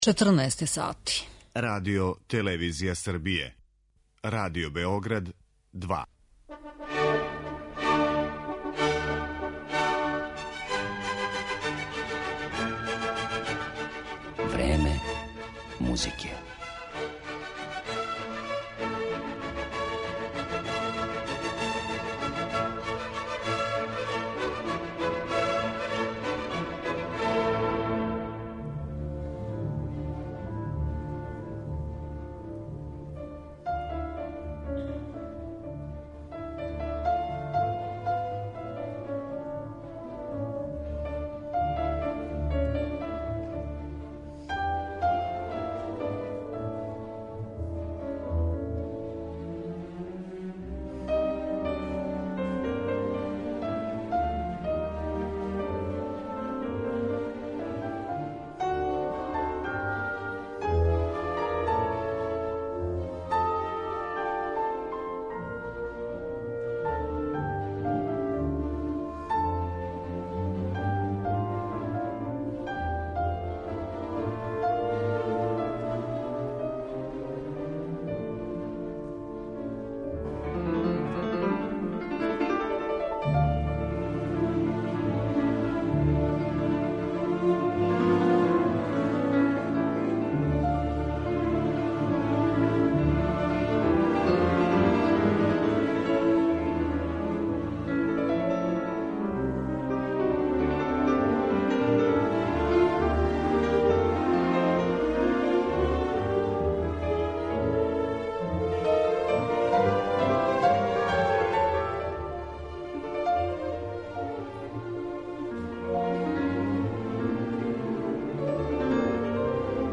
У емисији ћемо слушати његове интерпретације Рахмањинова, Листа, Моцарта, Равела и Чајковског.